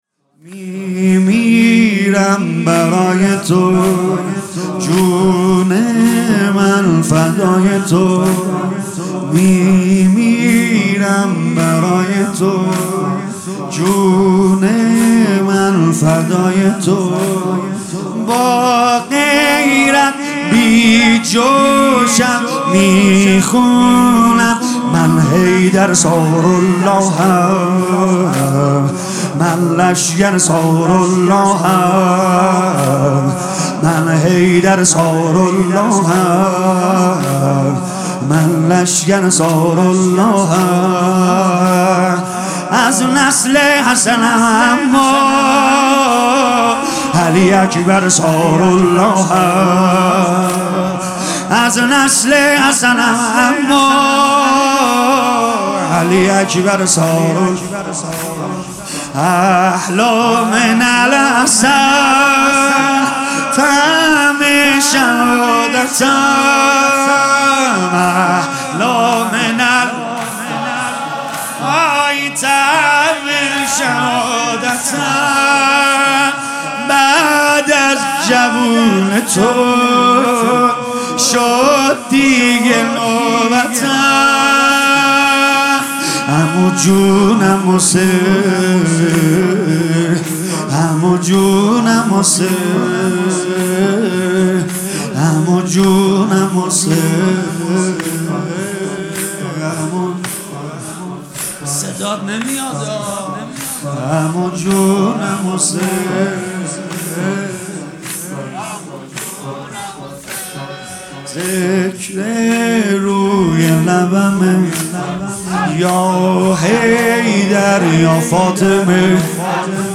شب پنجم محرم ۹۹ - هیئت فدائیان حسین
سید رضا نریمانی زمینه هیئت فدائیان حسین مداحی زمینه